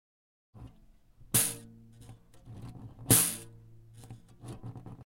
ラトリング（rattling、ビビリ音）のないこと、ハム（hum、弦鳴り）のない状態で
rattle.MP3